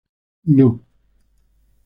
Uitgespreek as (IPA) /ˈno/